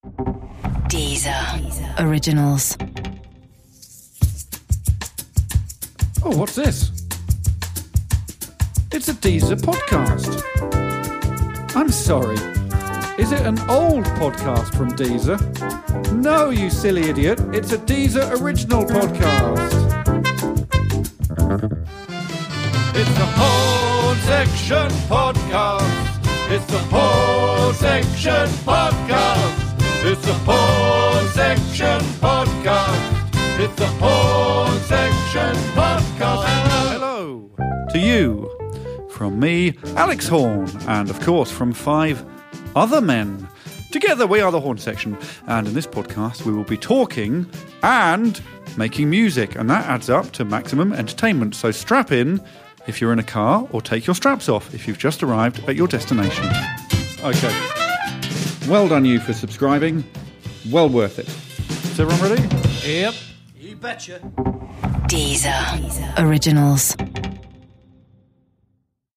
Coming soon, it's The Horne Section Podcast! A raucous, ridiculous and surprisingly satisfying show hosted by Alex Horne and featuring a whole host of special guests.